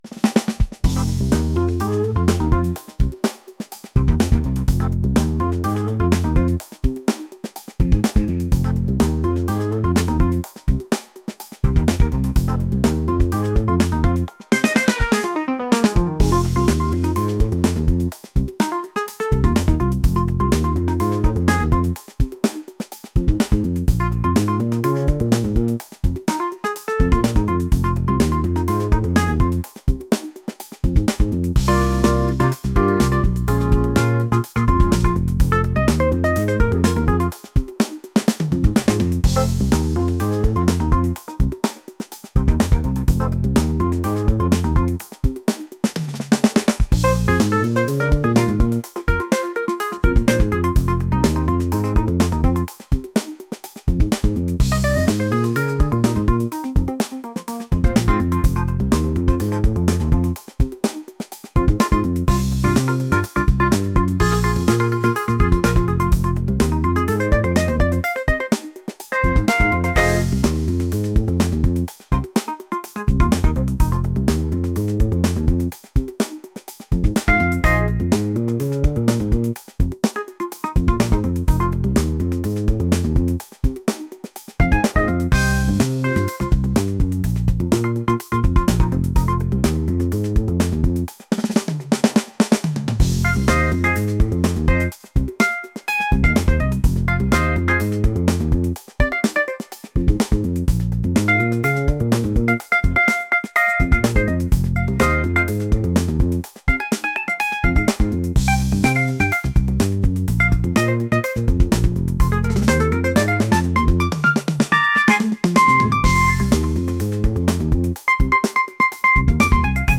funky | fusion | jazz